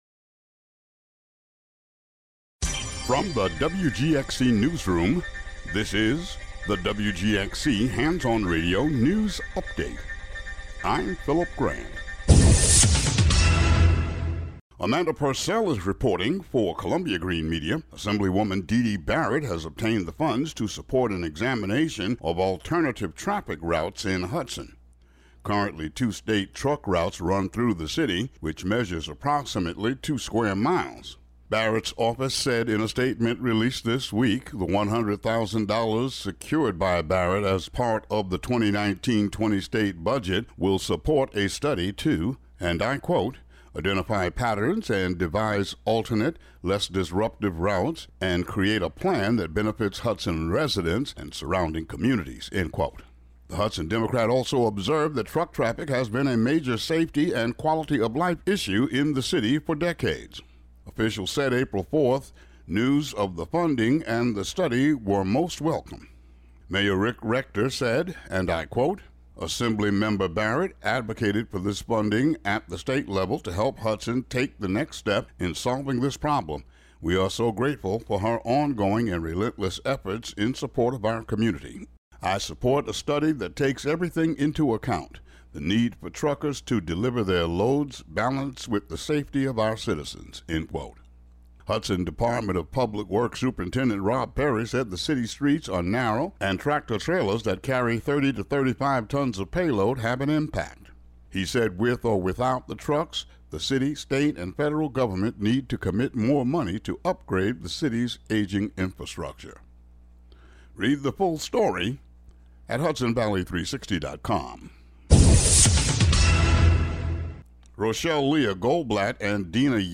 WGXC Local News Update Audio Link